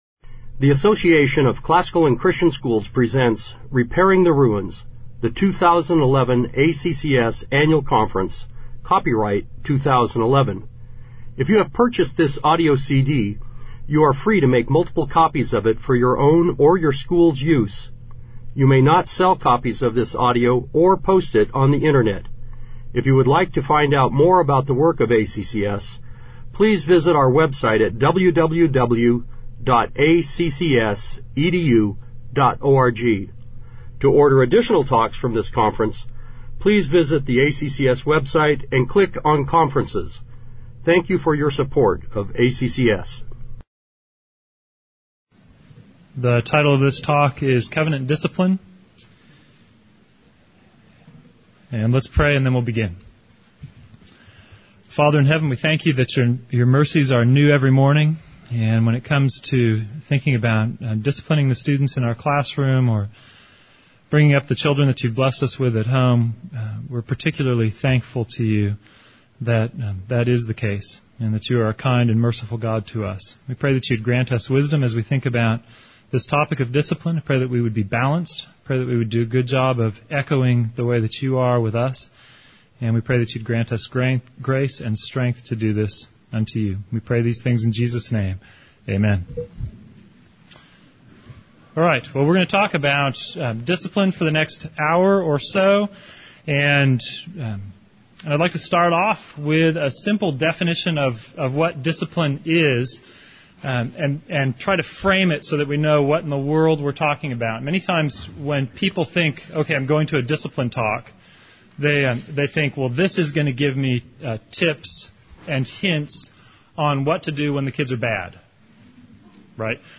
2011 Workshop Talk | 1:06:04 | All Grade Levels, Virtue, Character, Discipline